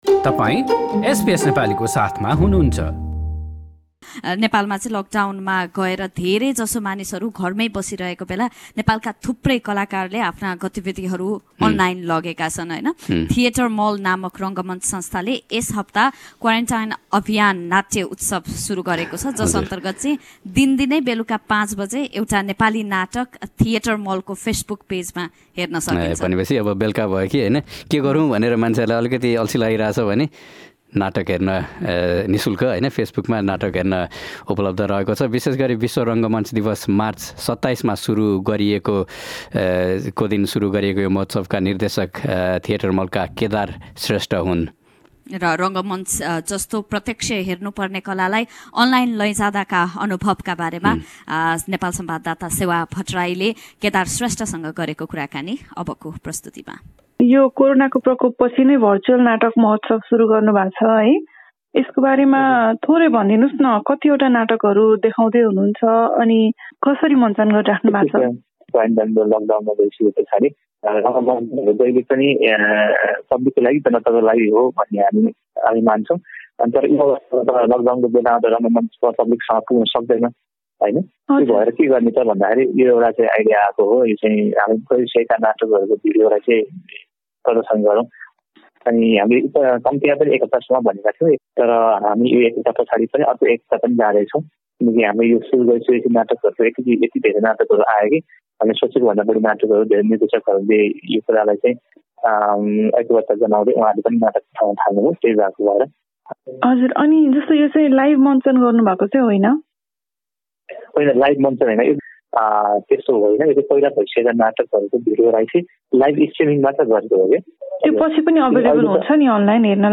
कुराकानीको अडियो माथि रहेको मिडिया प्लेयरमा सुन्नुहोस्।